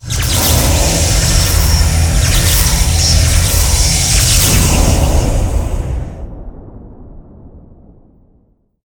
teleport_incoming.ogg